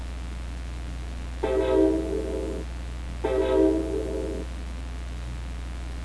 trainhorn.wav